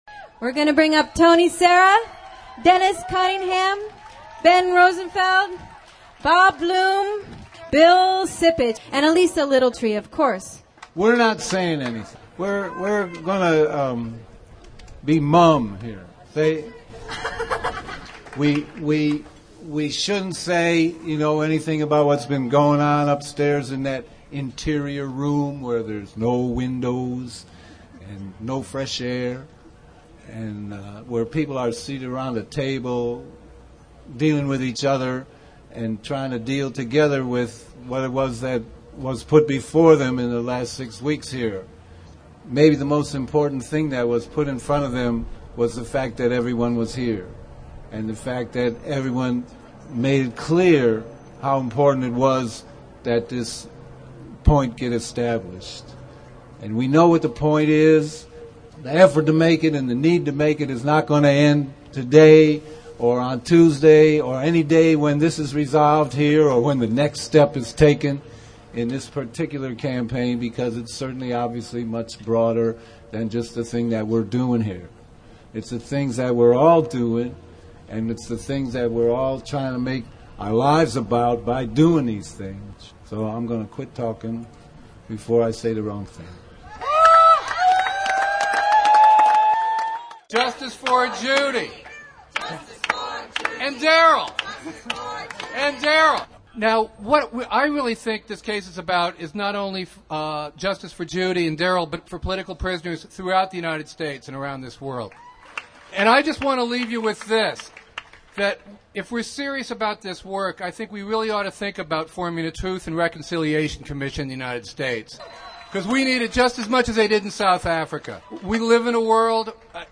The Earth First! Legal Team speaking at the Fiddle Down the FBI Rally, Oakland, May 24.
Attorneys for the plaintiffs in the Earth First! vs. FBI and Oakland police civil rights trial speaking at the Fiddle Down the FBI Rally, May 24th, 2002.